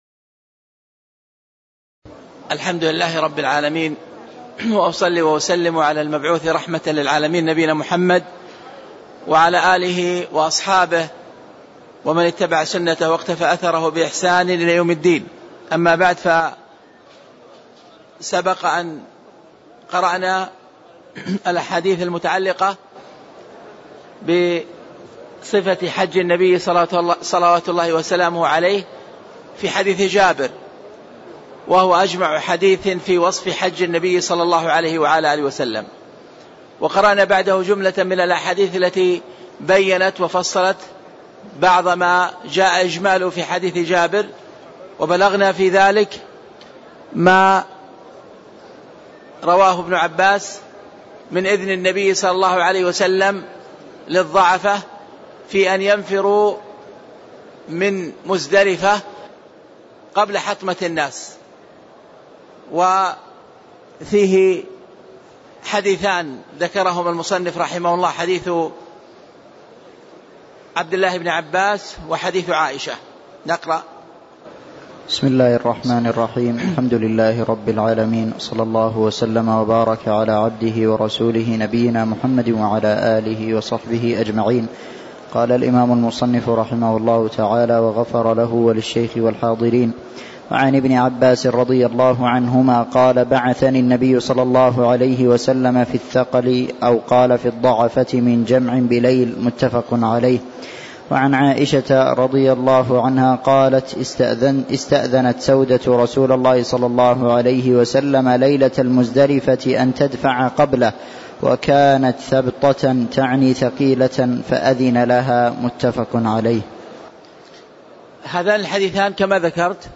تاريخ النشر ١١ ذو القعدة ١٤٣٦ هـ المكان: المسجد النبوي الشيخ